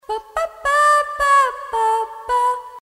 Play Baaa 4 - SoundBoardGuy
Play, download and share Baaa 4 original sound button!!!!
baaa-4.mp3